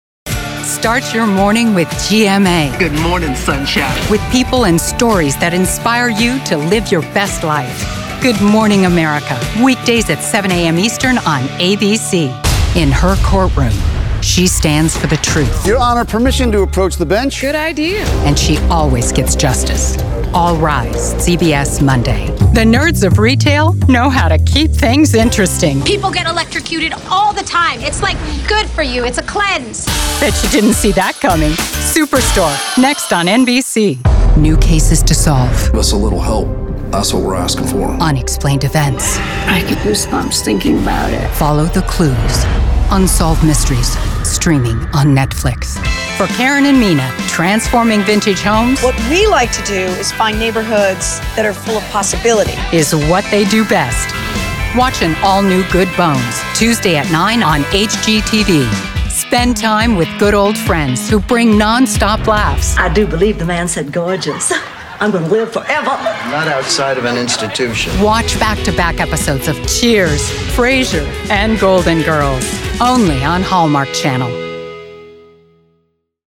TV Promo Demo
Neutral US, RP-British, German, & French
Middle Aged